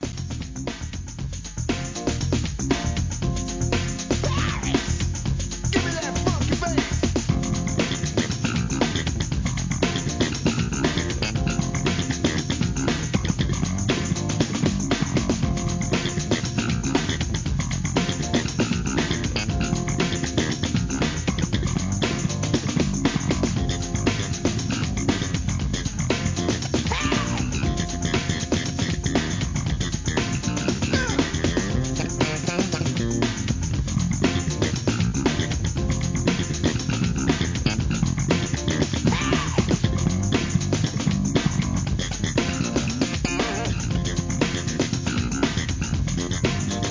HIP HOP/R&B
ACID JAZZ調のFUNKYブレイクビーツ!!